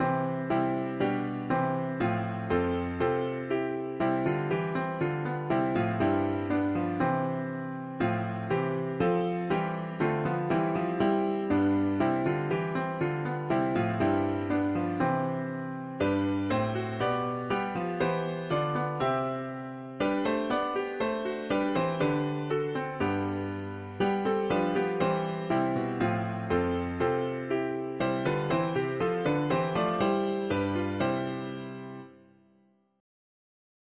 Alleluia All creaation join to say: Alleluia Raise your joys and triumphs high: Alleluia Sing, O heav’n, and earth rep… english christian easter 4part chords